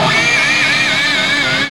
Index of /90_sSampleCDs/Roland LCDP02 Guitar and Bass/GTR_GTR FX/GTR_Gtr Hits 1